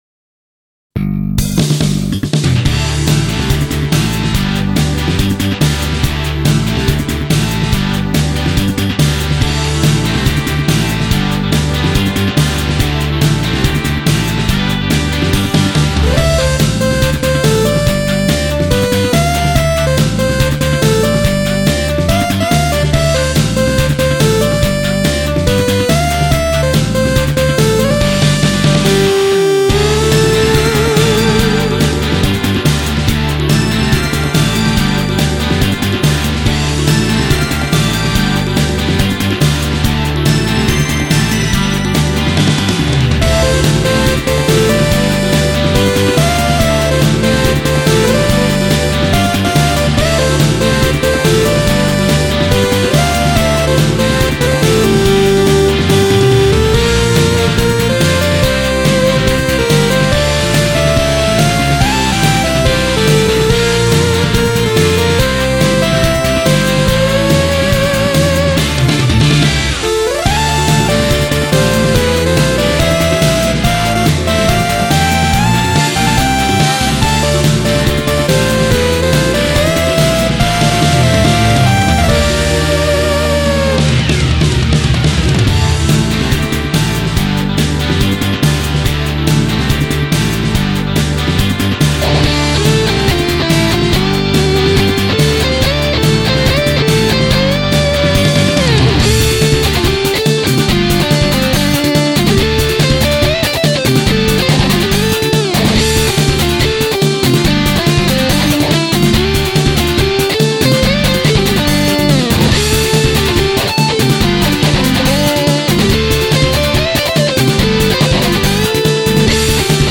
ファイルは「YAMAHA MU1000EX」「Roland SC-8850、INTEGRA-7」で制作したものを